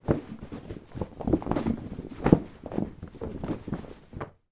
Звуки бытовые
Разворачивание ковра по частям на ковровом покрытии